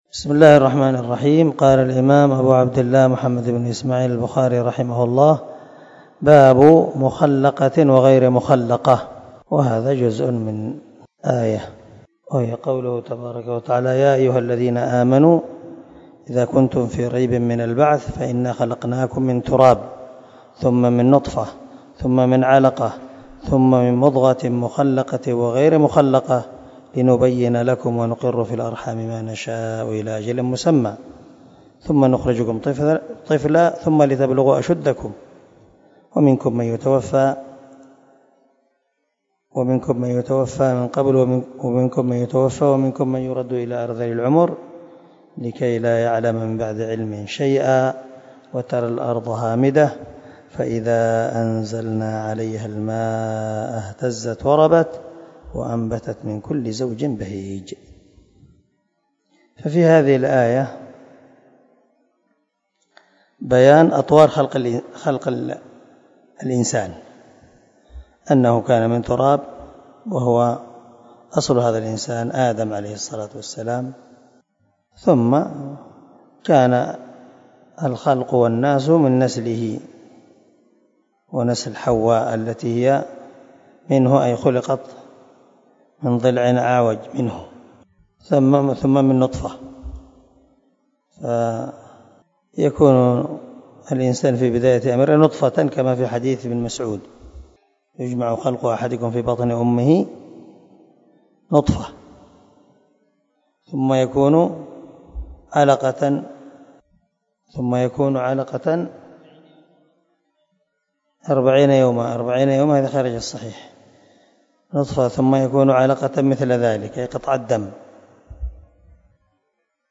248الدرس 15 من شرح كتاب الحيض حديث رقم ( 318 ) من صحيح البخاري